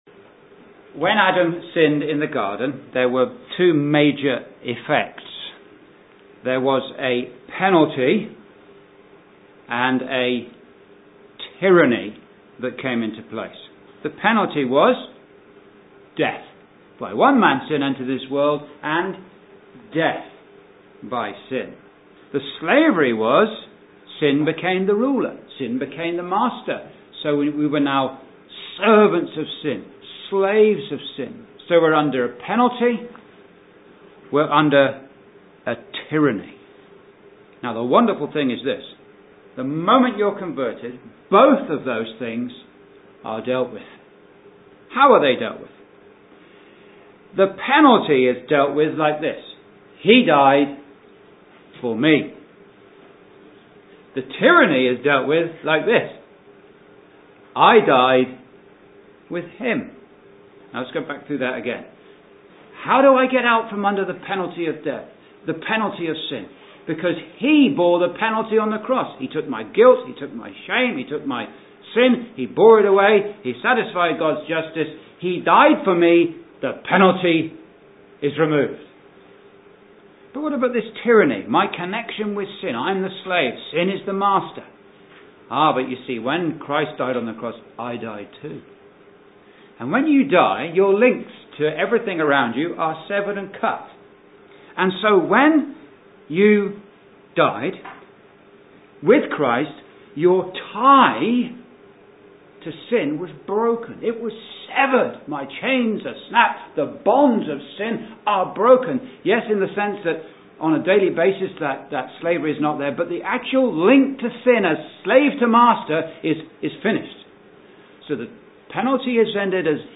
From its 3 references in Rom 6, Eph 4 and Col 3 “the old man” is seen to be what we were in Adam prior to conversion (our “old standing”), whereas the “flesh” is the evil principle within (our “old nature”) (Message preached 3rd Feb 2013)